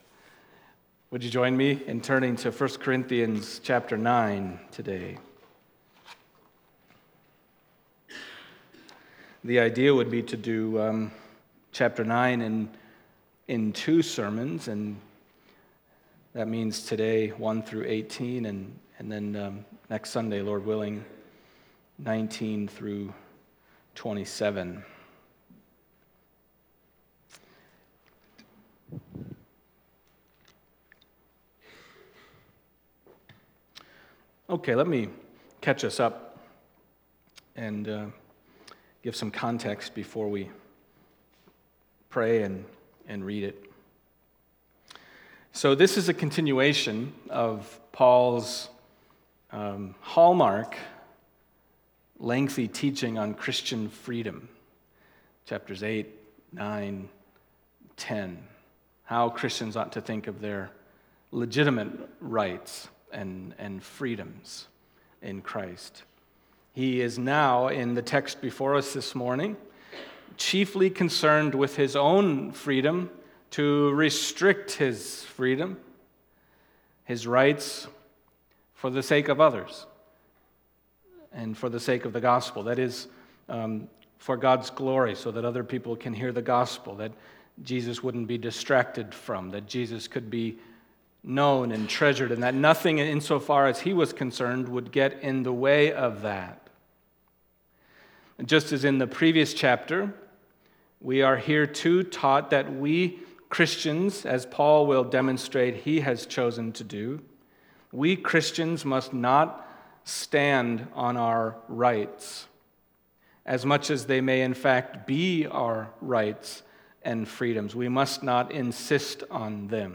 1 Corinthians Passage: 1 Corinthians 9:1-18 Service Type: Sunday Morning 1 Corinthians 9:1-18 « Where Does Child Killing Come From?